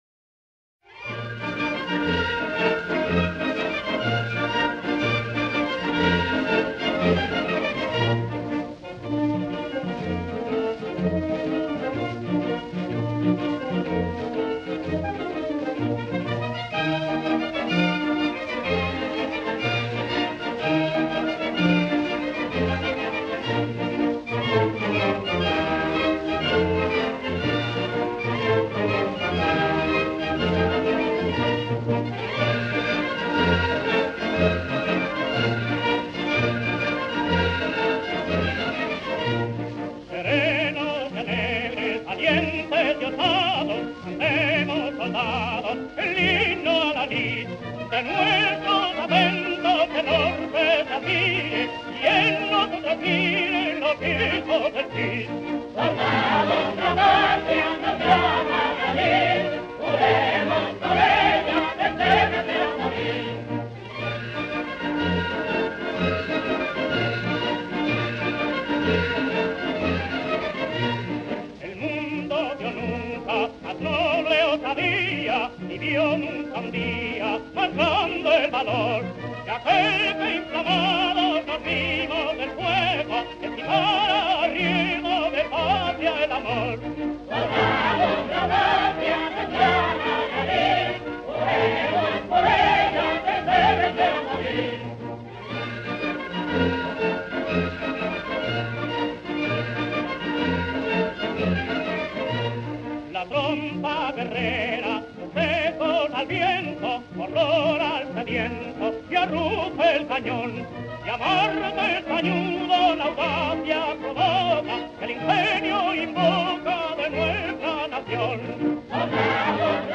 The national anthem of the Second Spanish Republic, Himno de Riego
Miguel Fleta (performer)